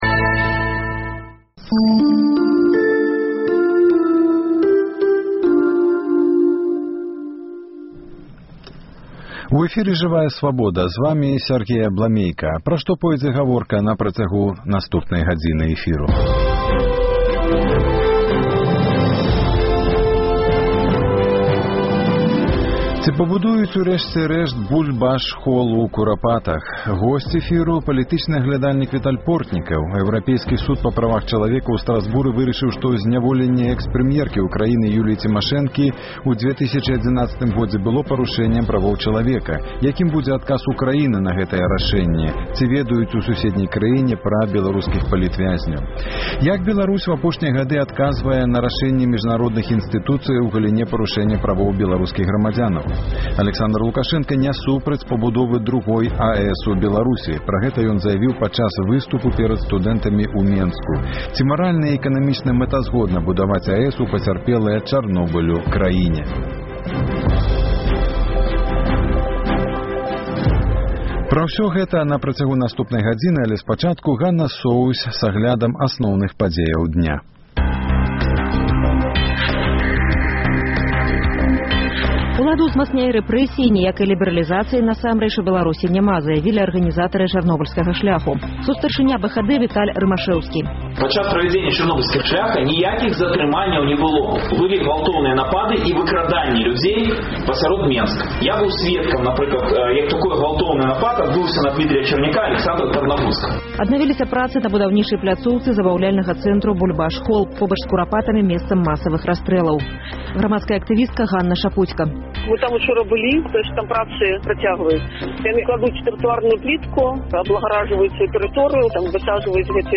Ці пабудуюць урэшце “Бульбаш-хол” у Курапатах? Госьць эфіру — палітычны аглядальнік Віталь Портнікаў.